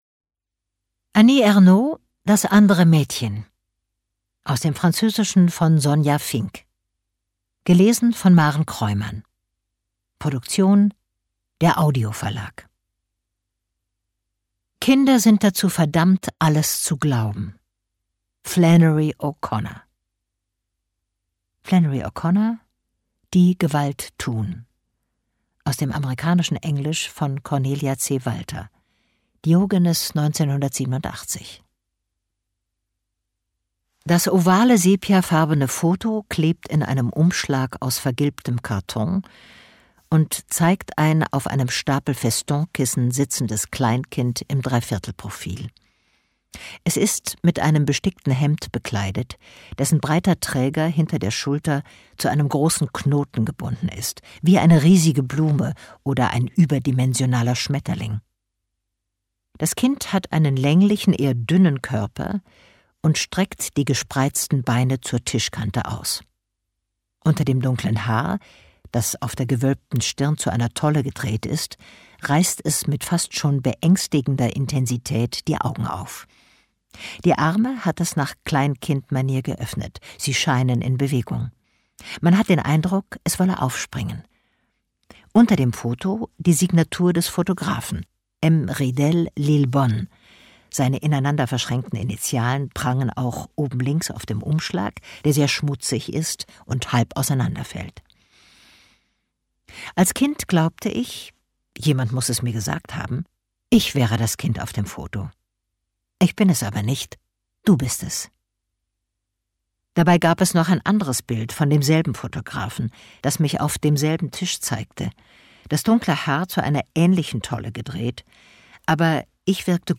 Ungekürzte Lesung mit Maren Kroymann (1 CD)
Maren Kroymann (Sprecher)